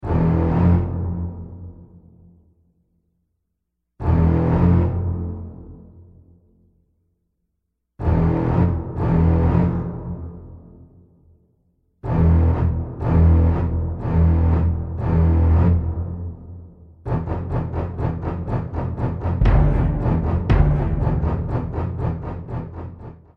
Musique